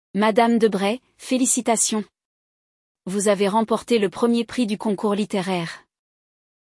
Hoje, trazemos um diálogo envolvente e cheio de surpresas: Madame Debray recebe uma ligação inesperada.
• Imersão total: 99% do episódio em francês, para você realmente absorver o idioma.
• Treino de pronúncia: Pratique falando em voz alta, seguindo o modelo dos falantes nativos.